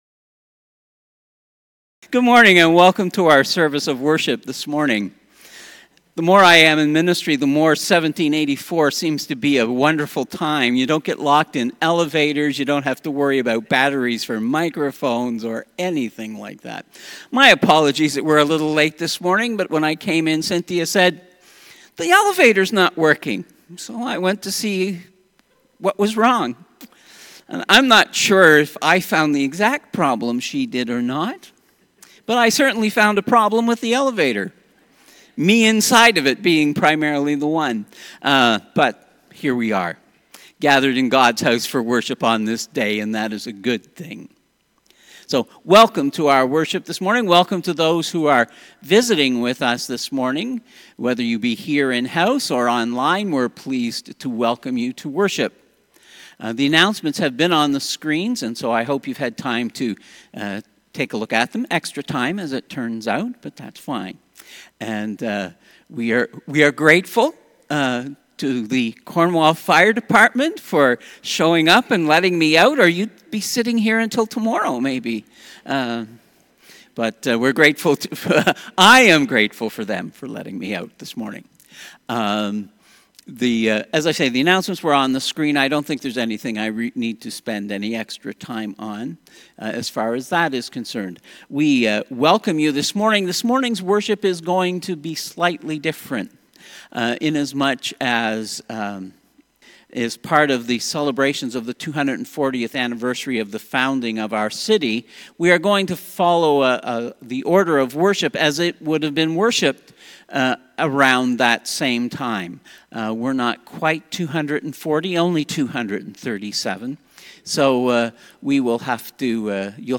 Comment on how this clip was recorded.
This service is modeled after a typical 18th century Presbyterian Worship Service in honour of the 240th anniversary of the founding of Cornwall, Ontario, then known as New Johnstown.